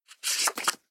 Card_Flip.mp3